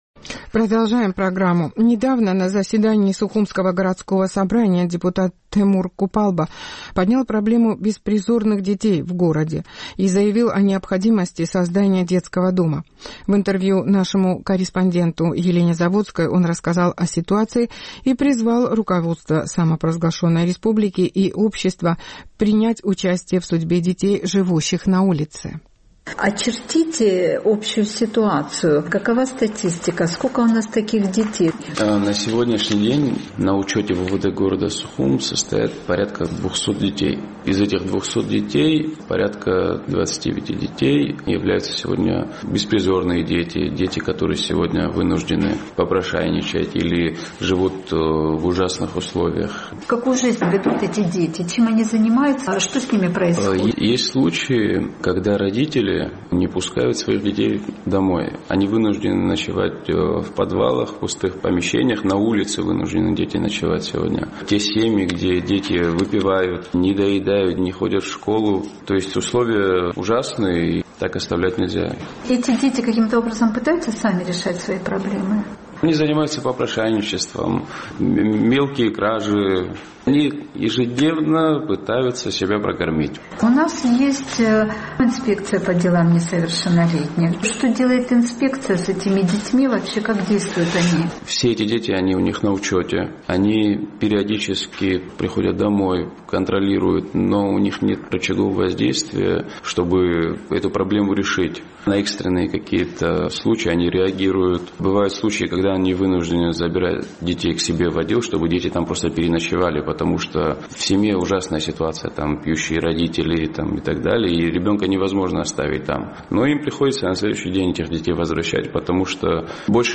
В интервью он рассказал о ситуации и призвал руководство страны и общество принять участие в судьбе детей, живущих на улице.